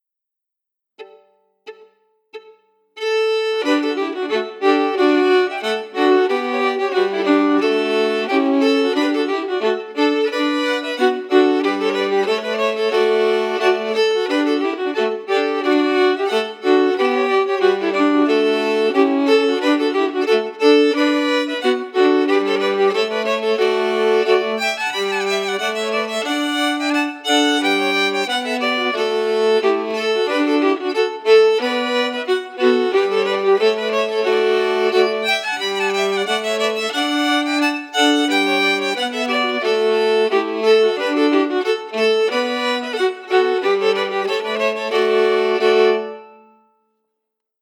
Key: D
Form: Reel/Song
Harmony emphasis
M: 4/4